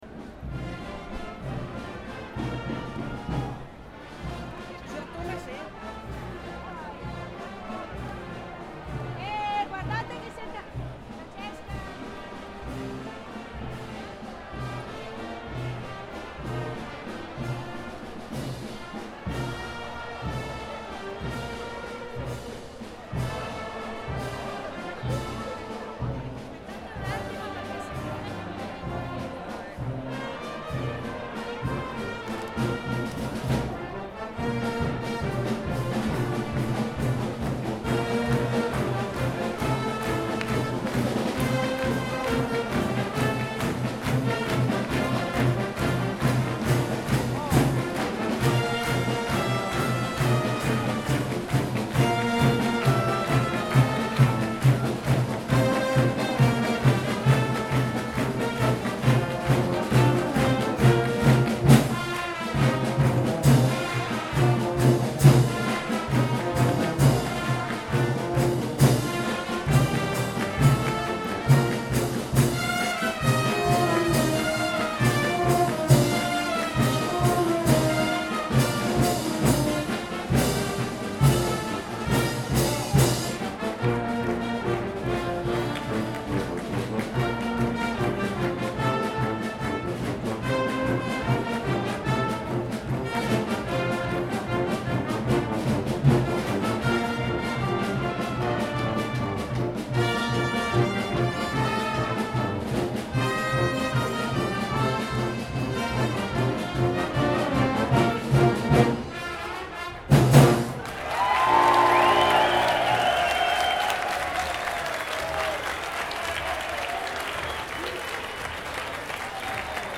Rumore
Microfoni binaurali stereo SOUNDMAN OKM II-K / Registratore ZOOM H4n
La-banda-degli-alpini.mp3